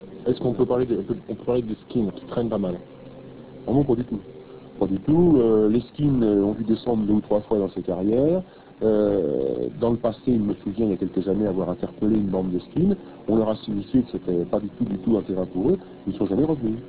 Extracts from France Inter radio show "Les Visiteurs du Noir"